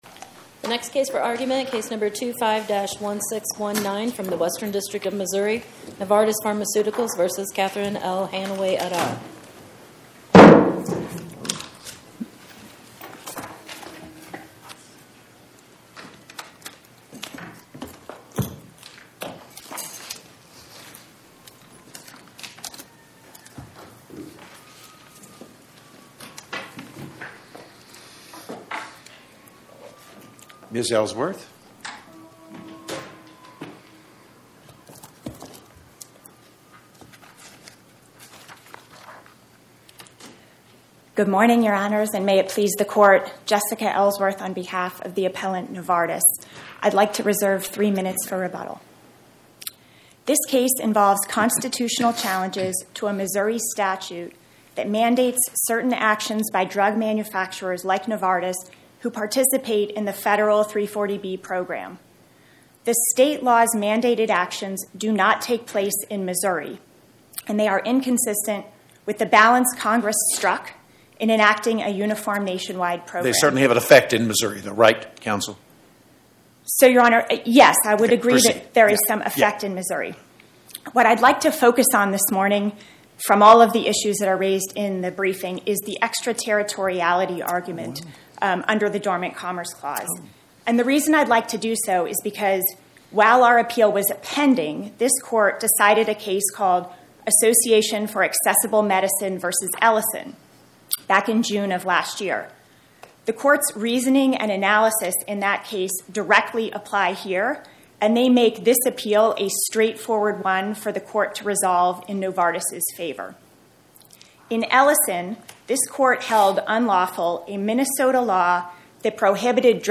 My Sentiment & Notes 25-1619: Novartis Pharmaceuticals Corp. vs Catherine L. Hanaway Podcast: Oral Arguments from the Eighth Circuit U.S. Court of Appeals Published On: Thu Jan 15 2026 Description: Oral argument argued before the Eighth Circuit U.S. Court of Appeals on or about 01/15/2026